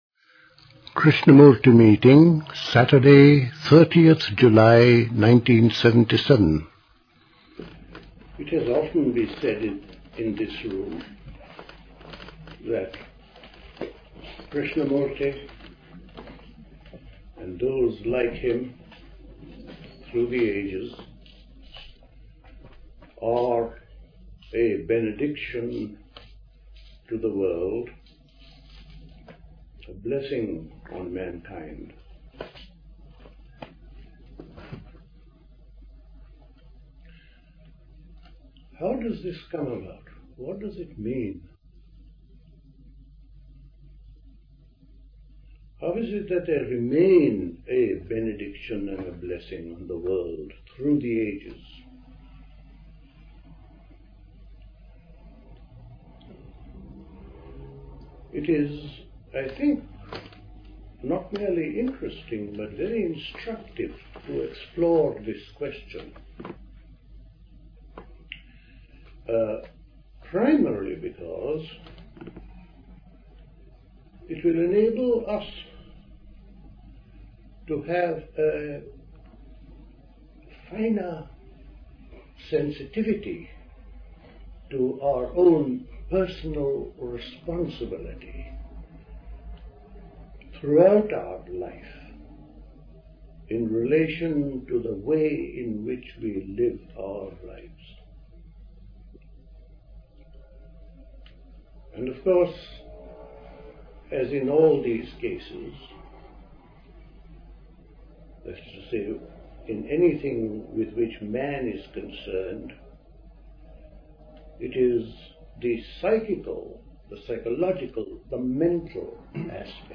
Recorded at a Krishnamurti meeting.